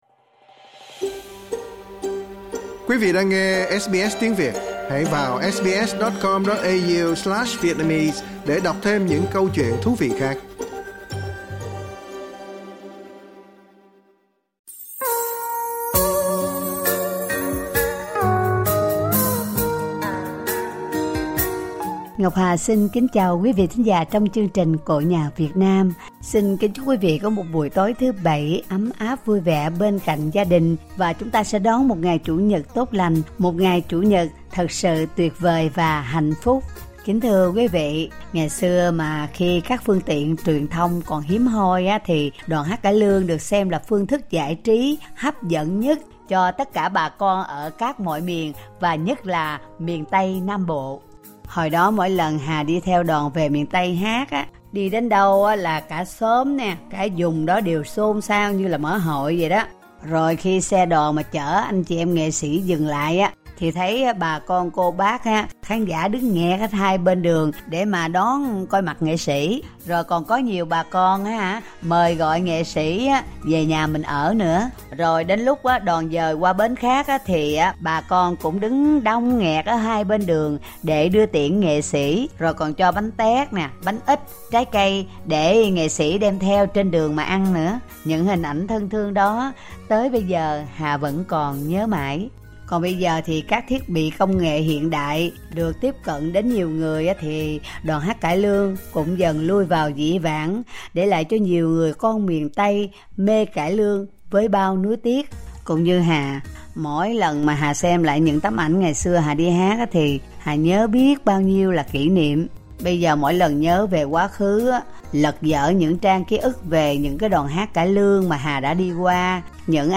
trích đoạn cải lương